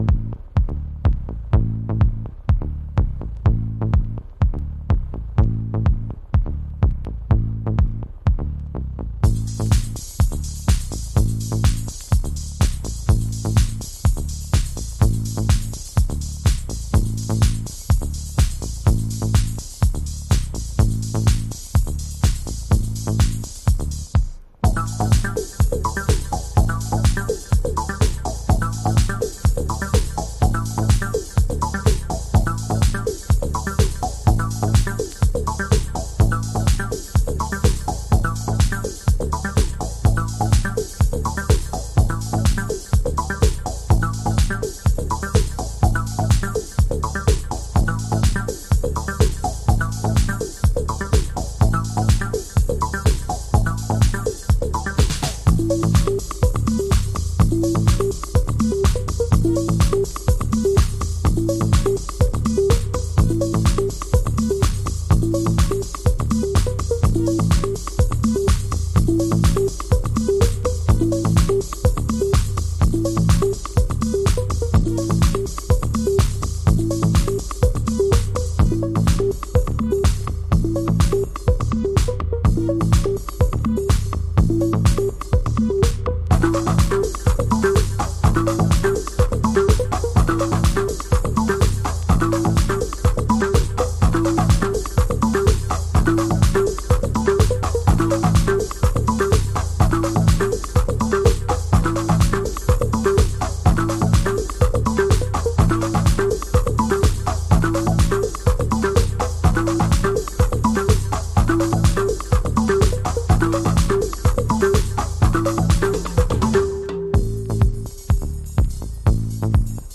あらゆるヴィンテージ機材を駆使して、80年代後期のシカゴハウスにアプローチ。